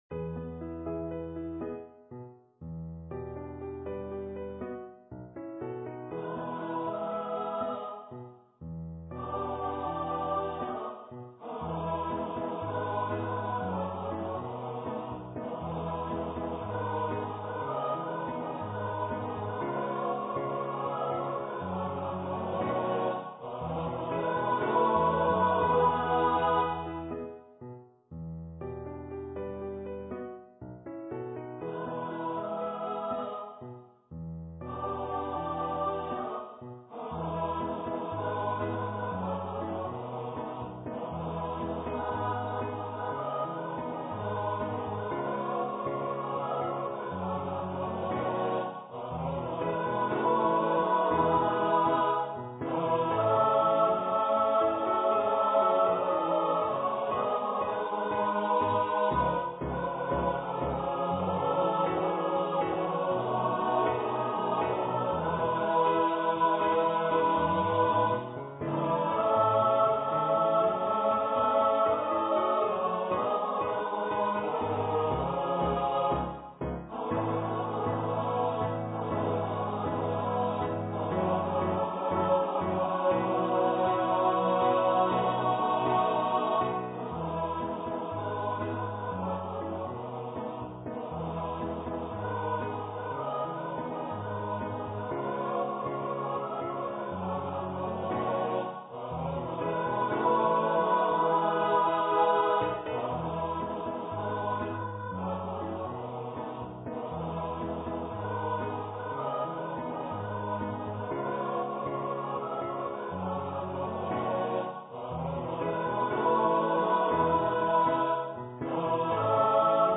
for SATB choir
Choir - Mixed voices (SATB)